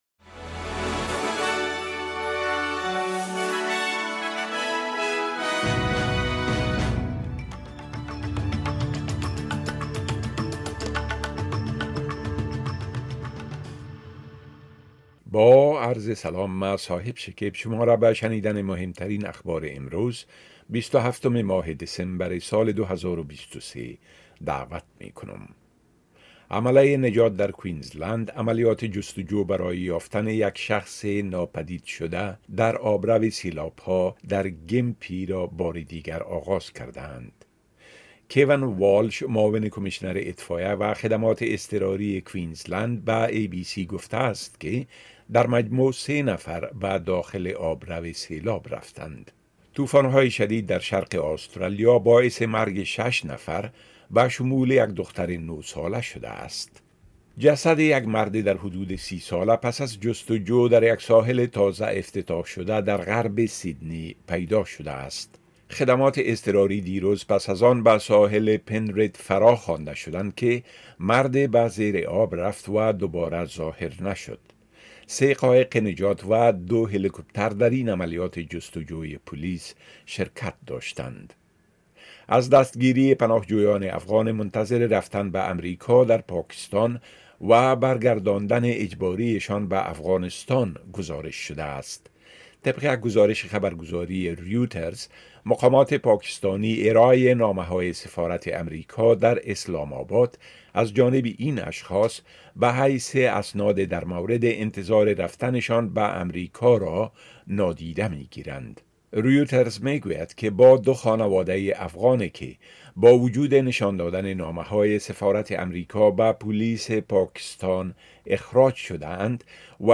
خلاصۀ مهمترين اخبار روز از بخش درى راديوى اس بى اس